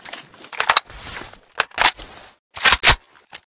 ak74m_reload_empty.ogg